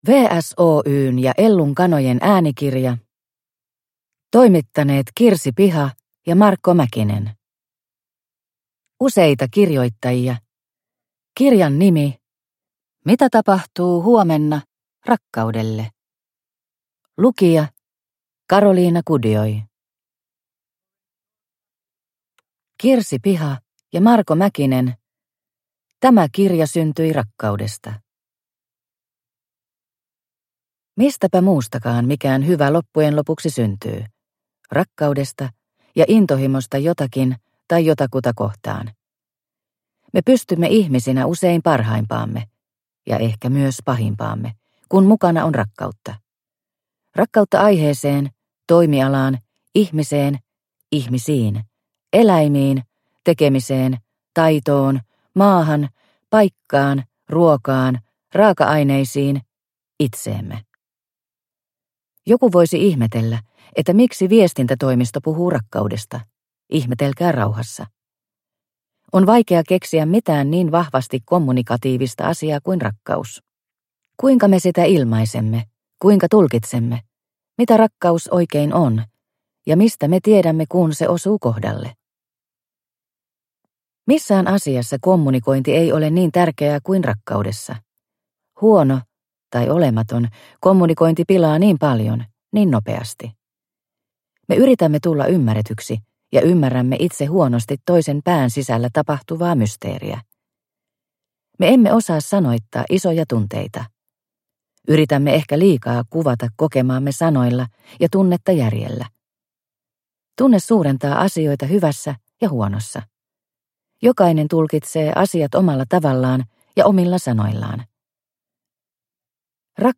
Mitä tapahtuu huomenna rakkaudelle? – Ljudbok